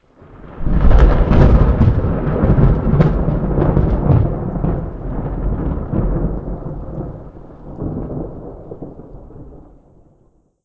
thunder3.wav